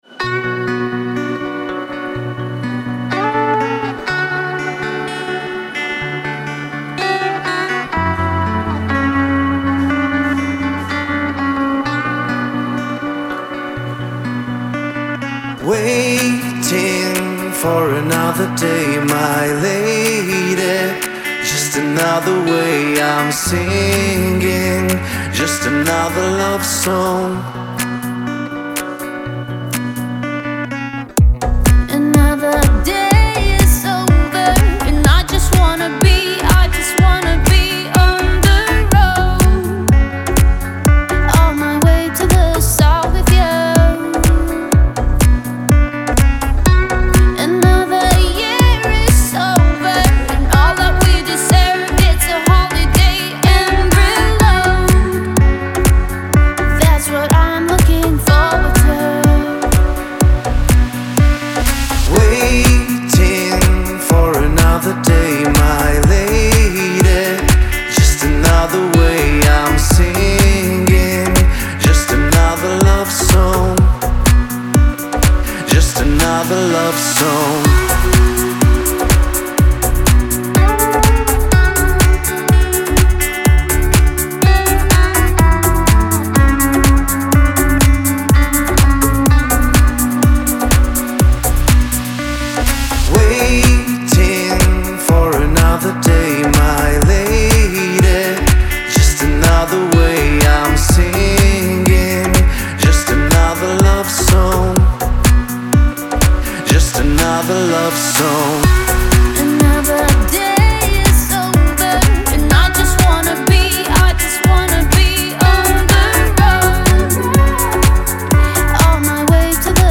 это яркий трек в жанре поп с элементами электроники